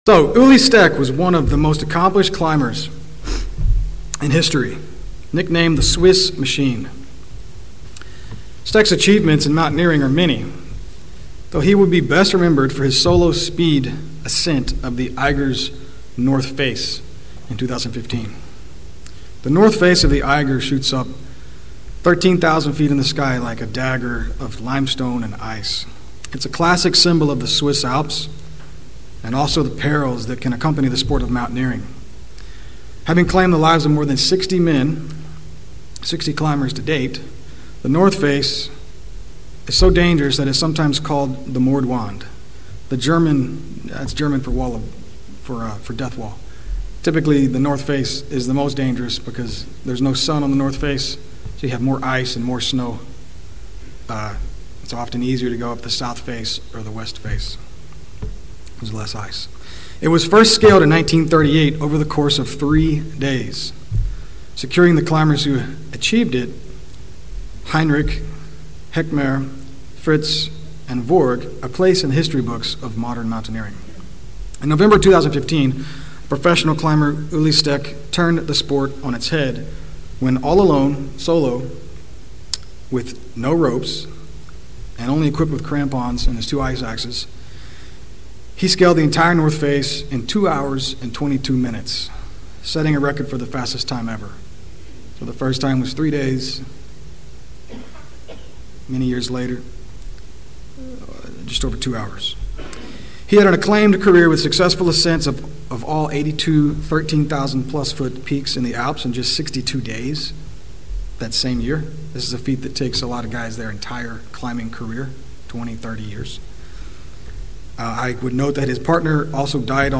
Given in Atlanta, GA Buford, GA